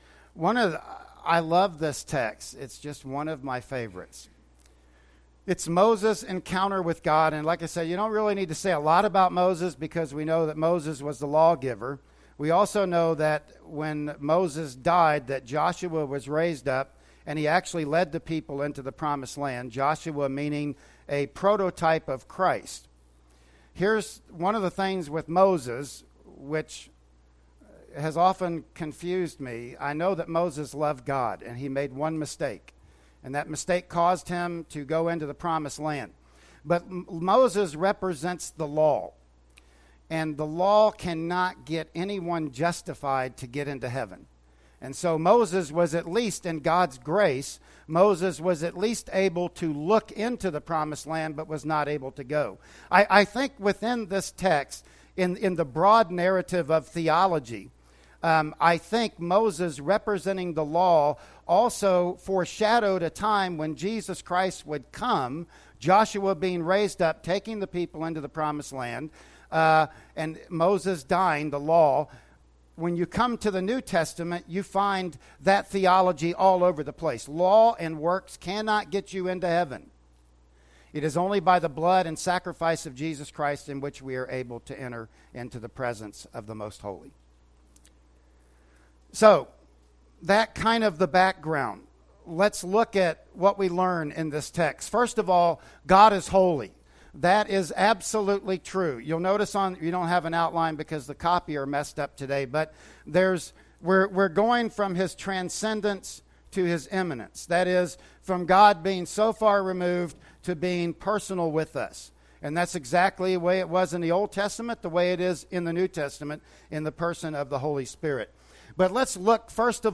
"Exodus 3:1-8" Service Type: Sunday Morning Worship Service Bible Text